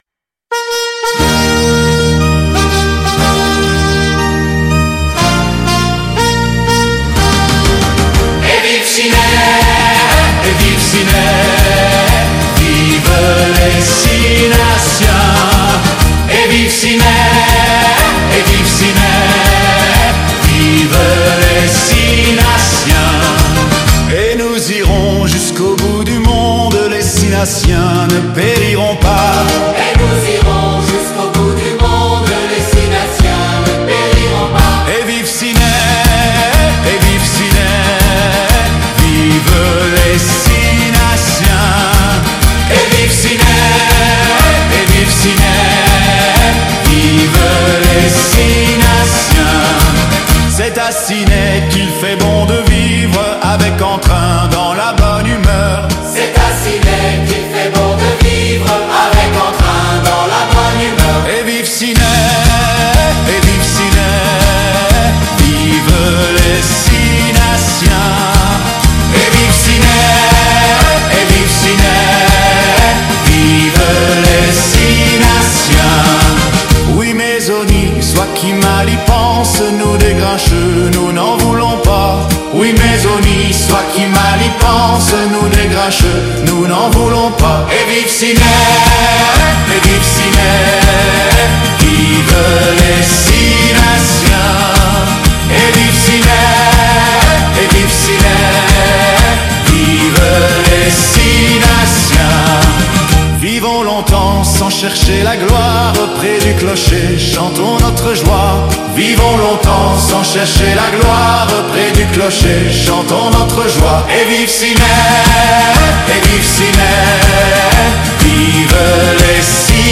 une version "rafraîchie" et plus moderne